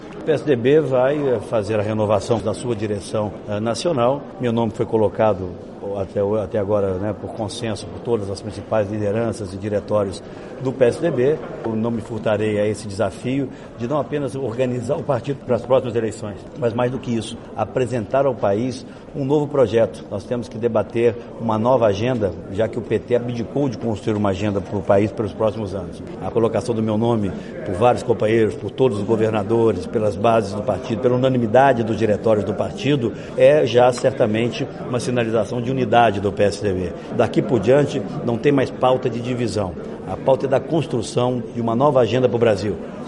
Fala do senador Aécio Neves